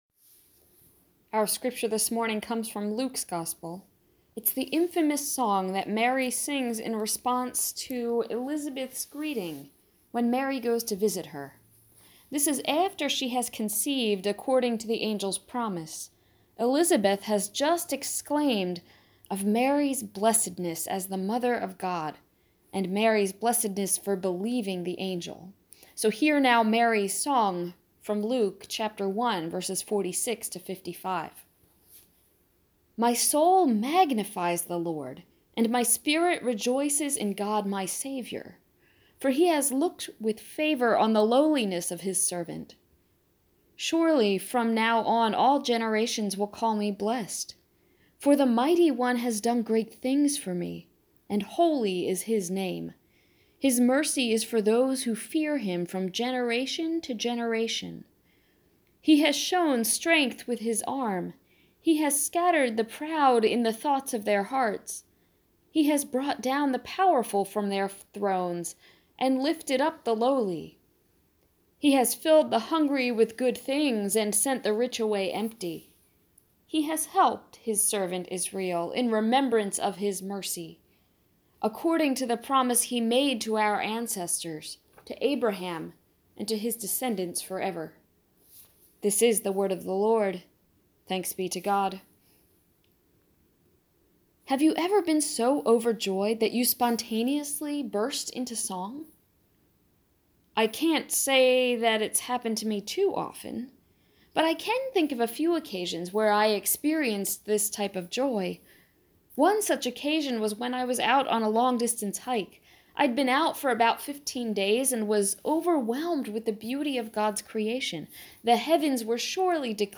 Preached 12/23/18